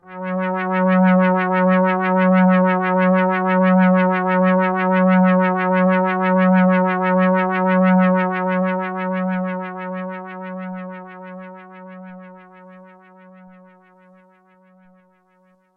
Roland JX8 P Mod Pad " Roland JX8 P Mod Pad F4 (66 F3 B540
标签： F4 MIDI音符-66 罗兰-JX-8P 合成器 单 - 注意 多重采样
声道立体声